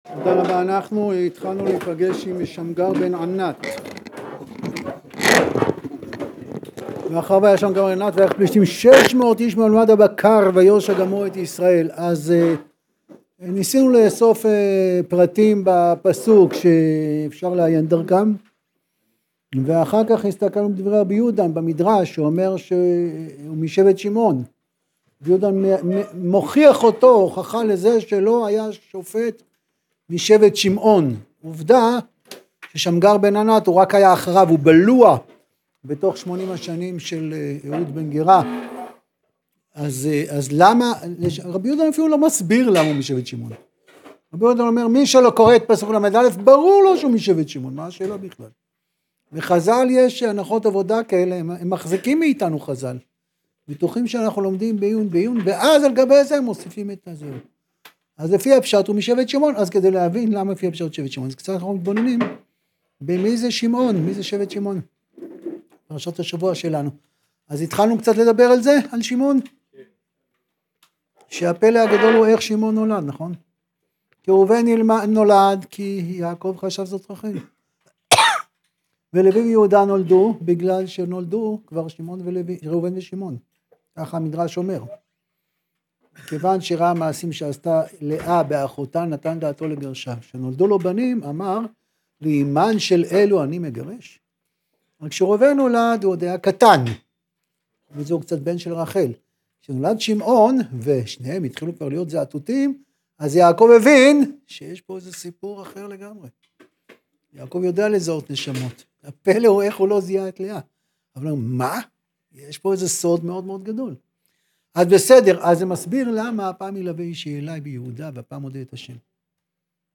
שיעור-תנך-11-MP3.mp3